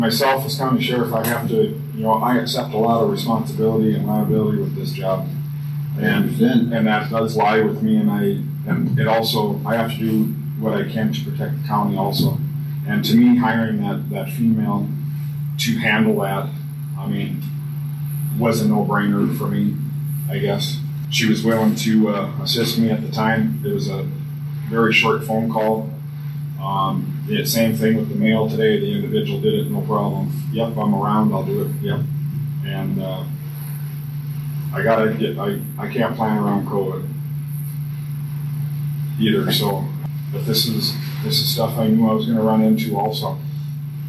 Walworth County Commission meeting December 15th
Sheriff Boll explained why he had to make that phone call for transport assistance.